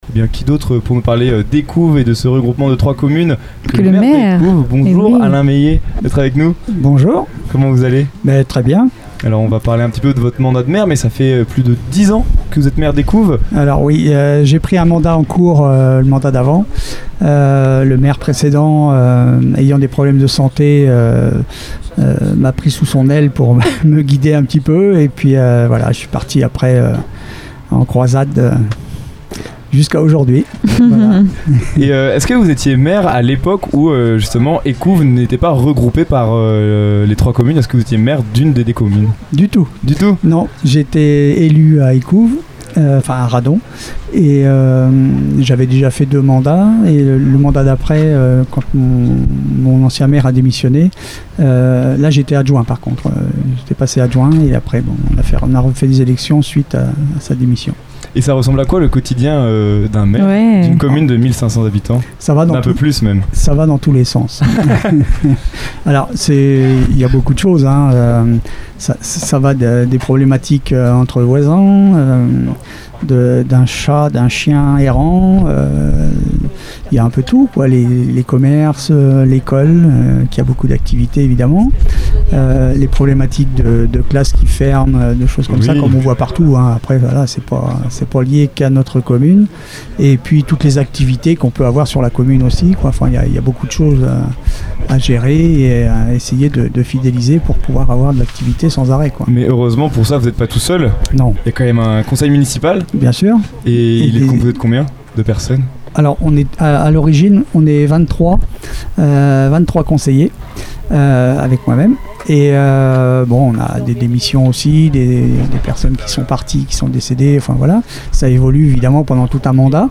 Lors du MysteryMachine Summer Tour, notre studiomobile s'est arrêté dans la commune d'Écouves, non loin d'Alençon, pour découvrir les secrets et histoires de ce village. Pour nous présenter Écouves, rien de mieux que le maire en personne : Alain MEYER.